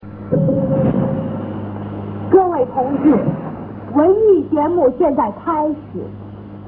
局名のない地下放送
同放送はジャミングを受けていた。